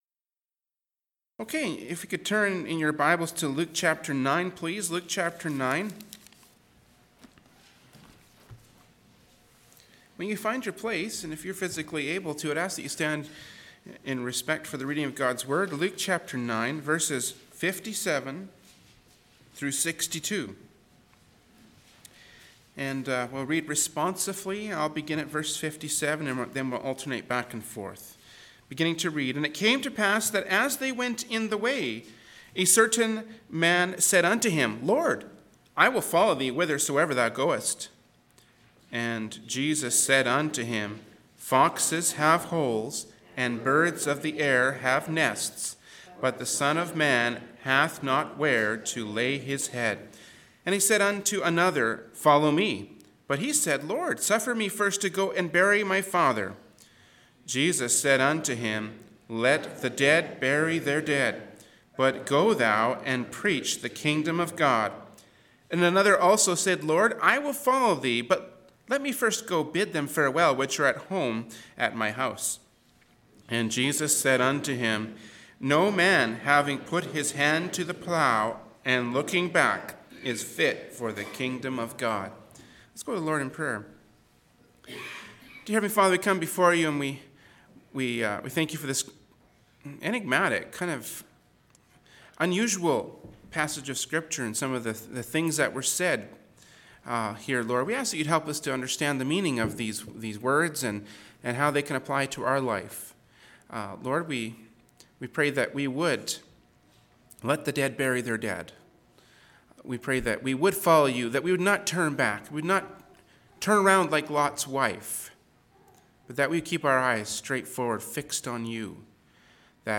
Genre: Preaching.
Passage: Luke 9:57-62 Service Type: Sunday Morning Worship Service